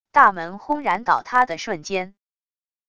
大门轰然倒塌的瞬间wav音频